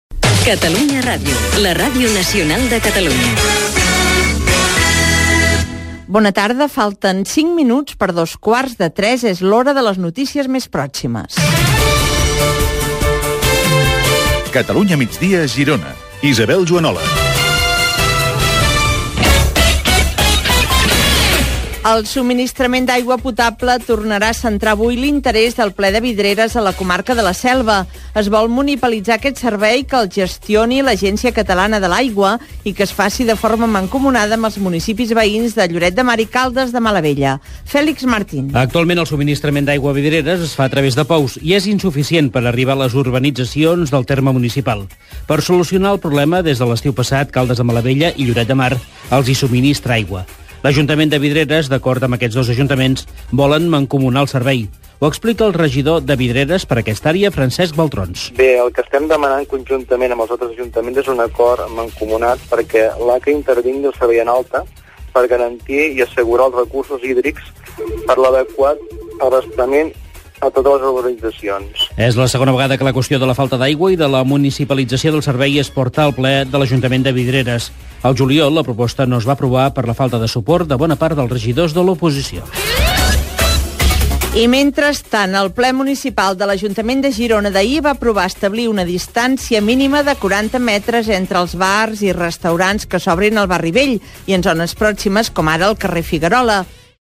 Catalunya migdia Girona: informació de proximitat - Catalunya Ràdio, 2015
Àudios: arxiu sonor i podcast de Catalunya Ràdio